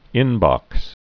(ĭnbŏks)